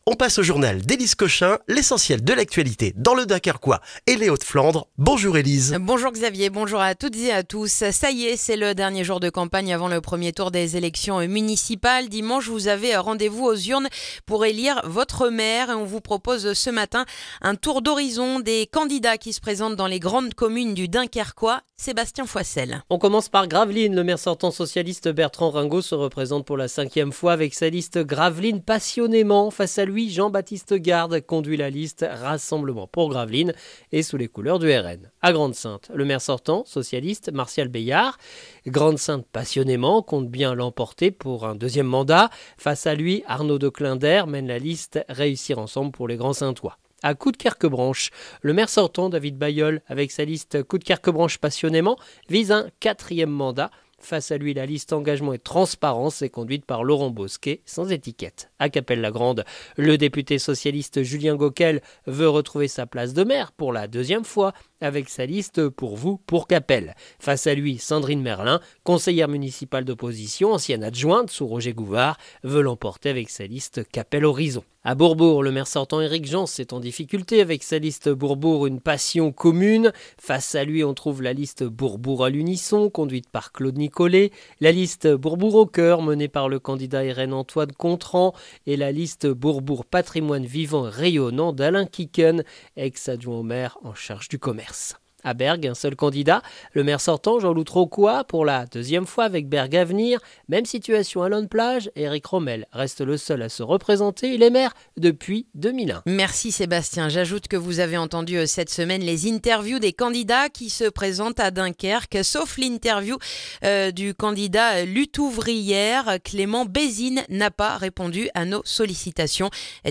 Le journal du vendredi 13 mars dans le dunkerquois